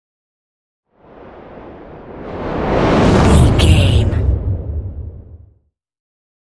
Dramatic whoosh to hit trailer
Sound Effects
Atonal
intense
tension
woosh to hit